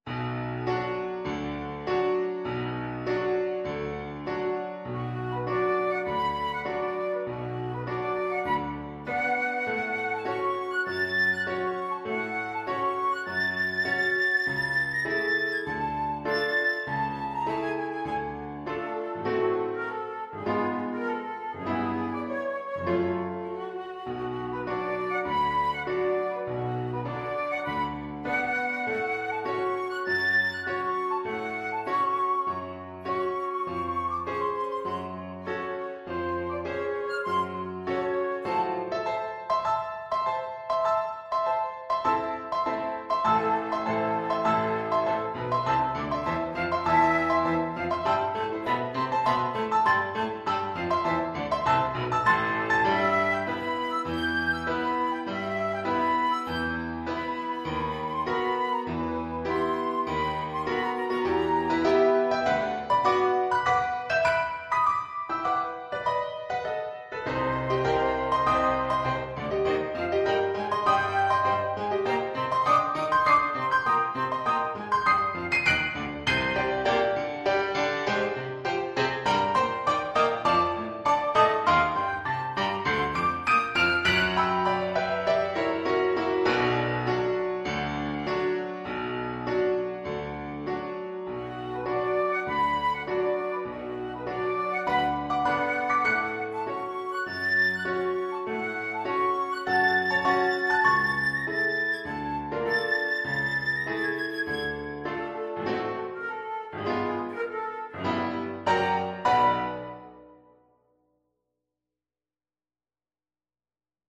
4/4 (View more 4/4 Music)
Classical (View more Classical Flute Music)